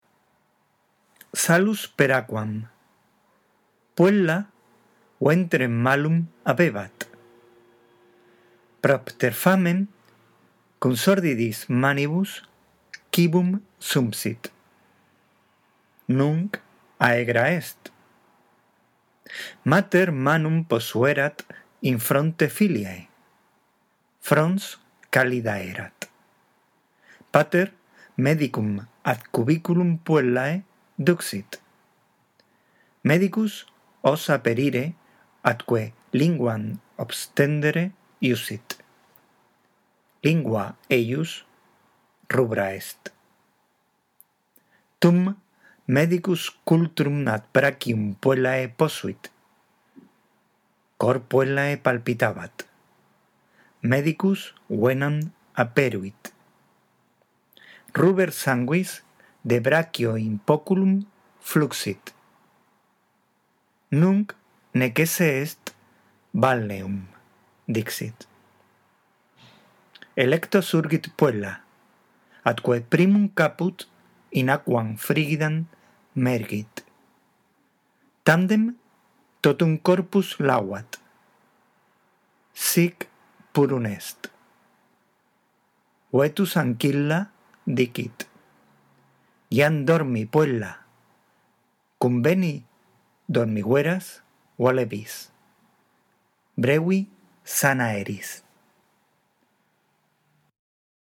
A. LECTURA DEL TEXTO